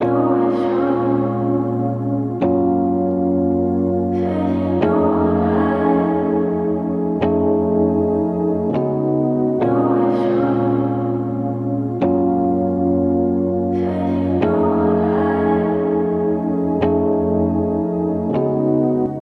Before Rotary PRO
Snappy_Rotary.mp3